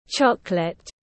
Nước sô-cô-la nóng tiếng anh gọi là chocolate, phiên âm tiếng anh đọc là /ˈtʃɒk.lət/
Chocolate /ˈtʃɒk.lət/